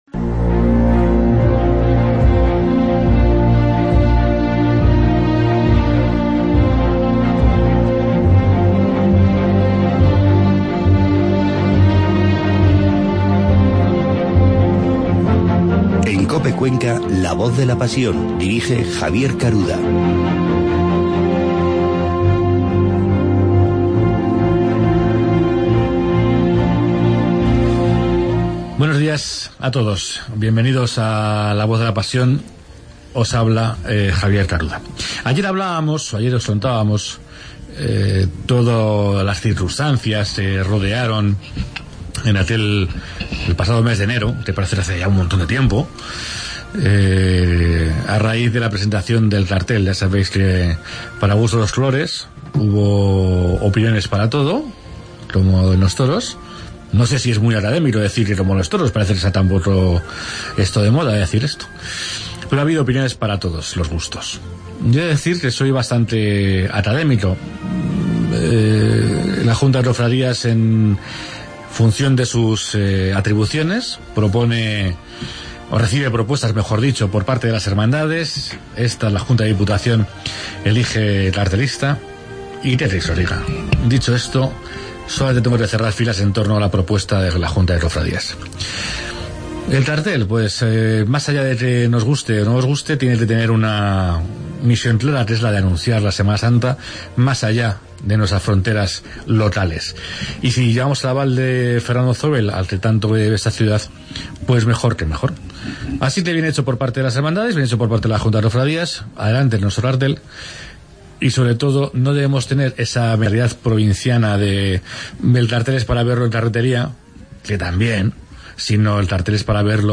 Hoy entrevistamos